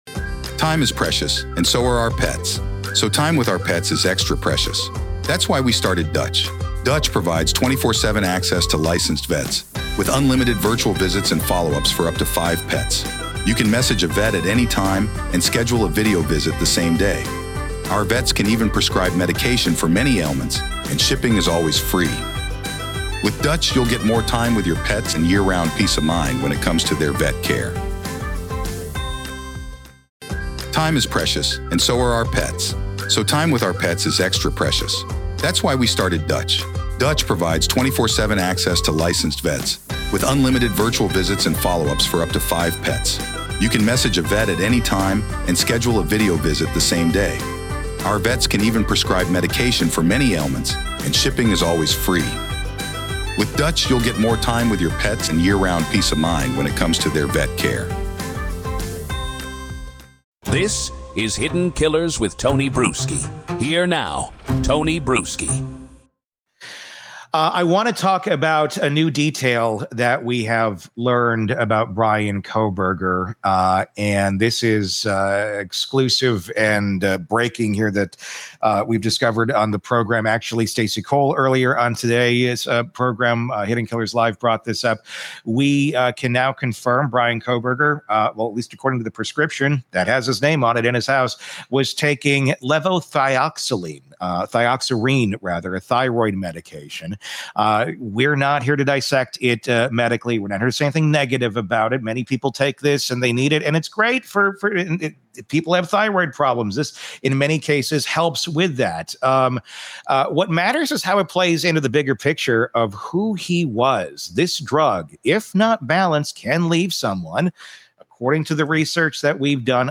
The discussion is not medical advice and does not suggest the drug causes violence; millions take thyroid medication safely.